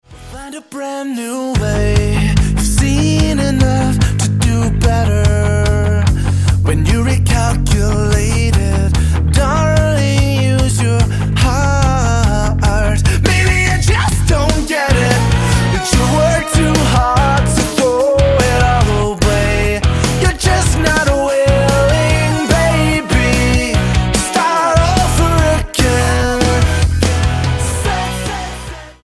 alternative rockers
Rock Album